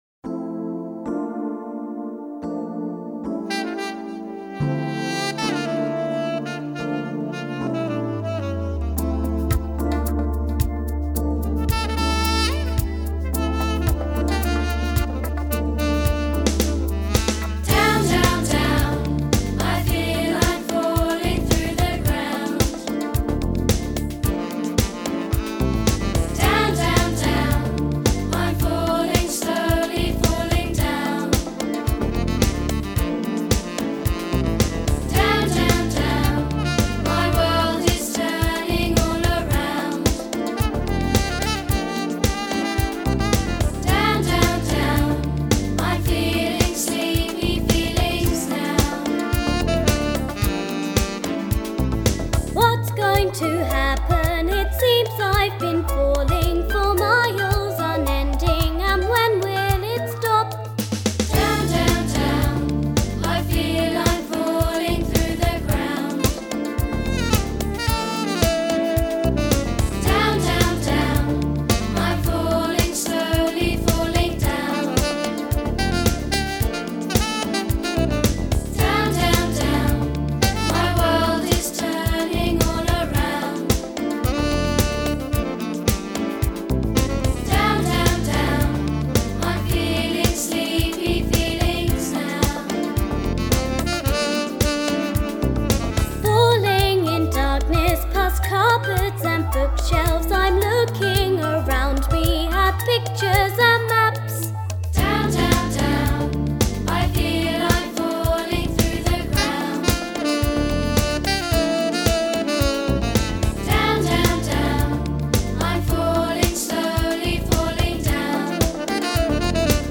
SONGS (Instrumental & Vocals)
(Vocal)